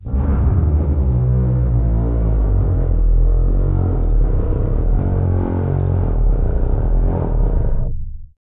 MOAN EL 10.wav